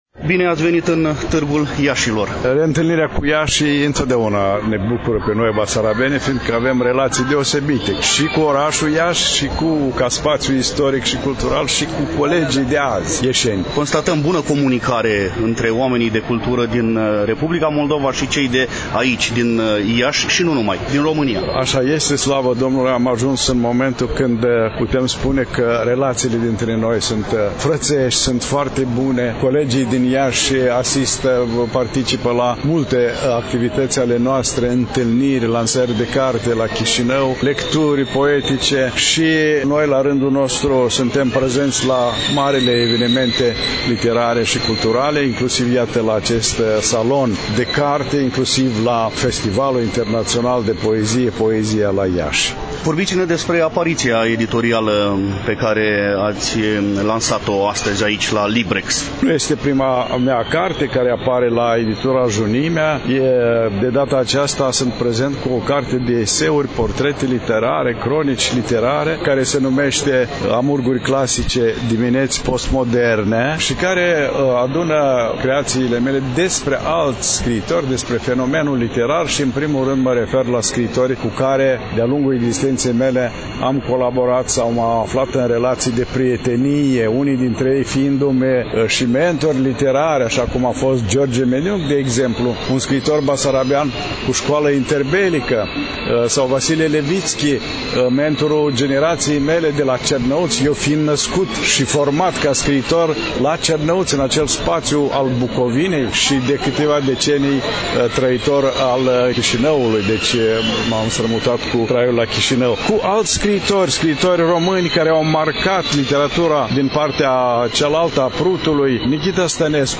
Dragi prieteni, vă reamintim că relatăm de la Târgul de carte LIBREX 2023, manifestare desfășurată, la Iași, în incinta Palas Mall, în perioada 10 – 14 mai.